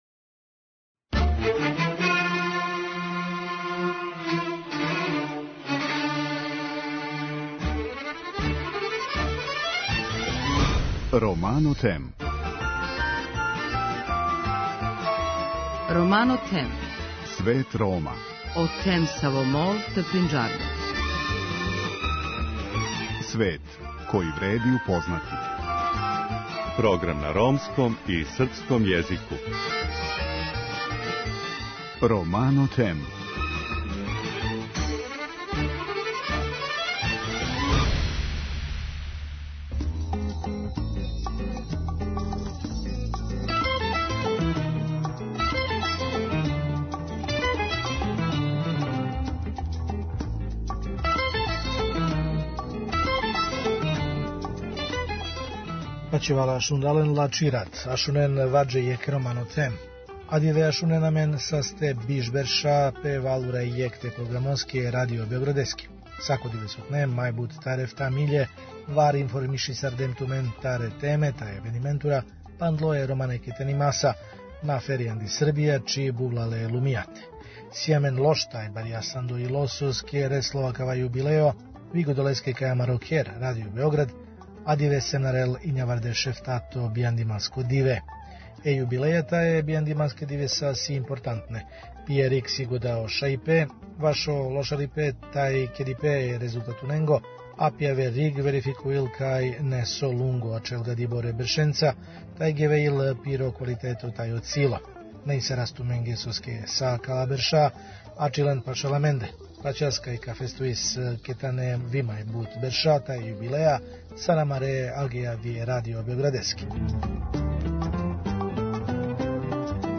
Тема данашње емисије је поверење Рома у европске институције. Наши гости ће говорити о томе зашто су резултати инклузије Рома у Европи више него скромни, иако је Европска унија у протекле две деценије у ту област уложила значајна финансијска средства.